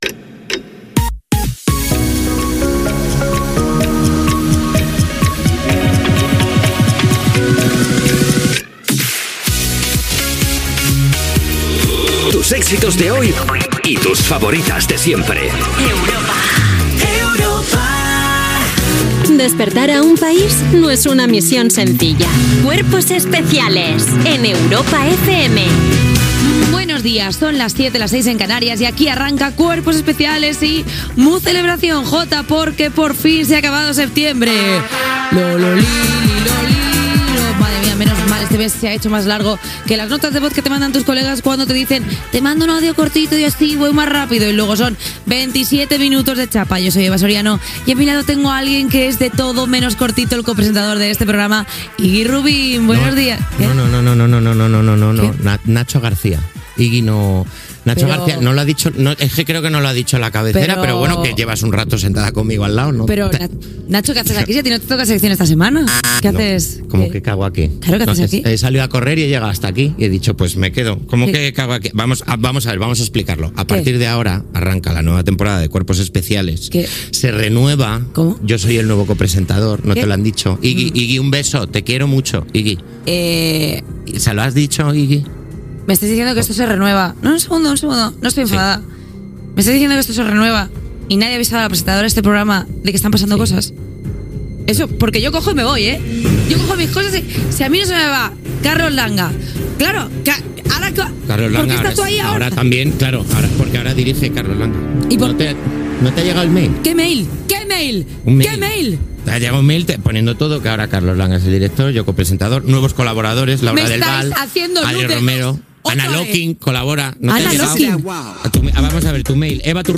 Indicatiu de la ràdio, careta, inici del programa.
Entreteniment